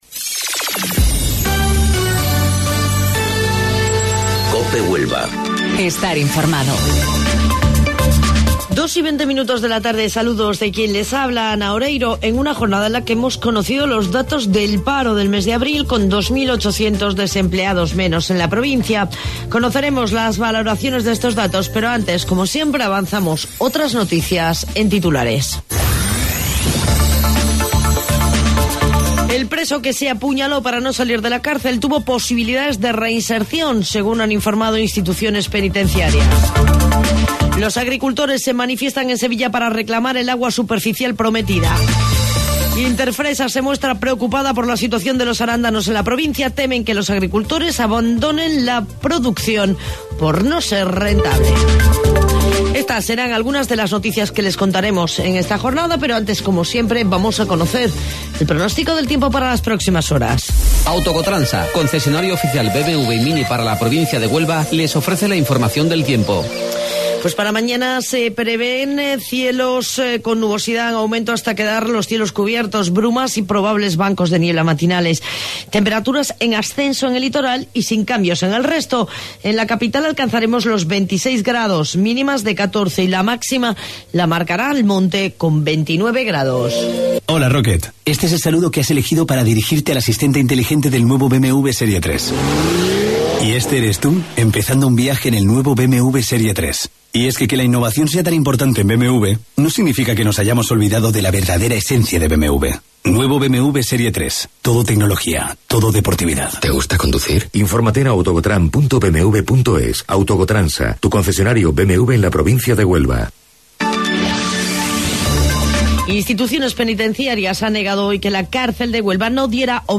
AUDIO: Informativo Local 14:20 del 6 de Mayo